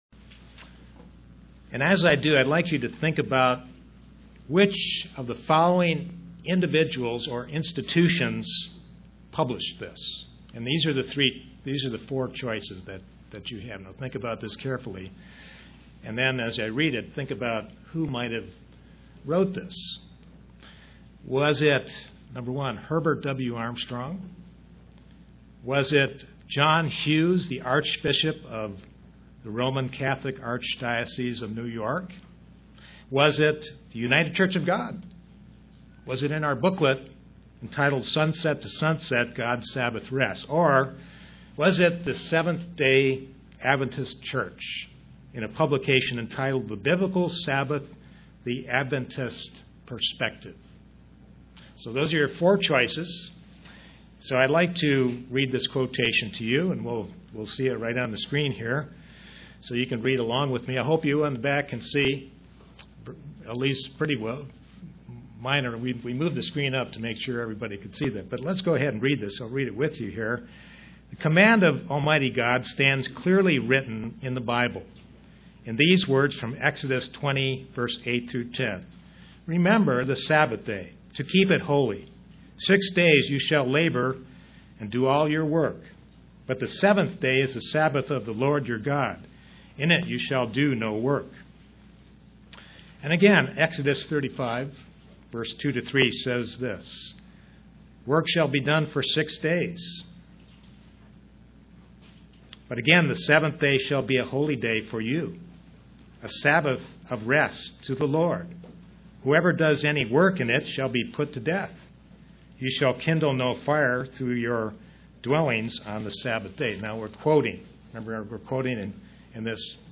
Print Proofs that the Sabbath is still in effect UCG Sermon Studying the bible?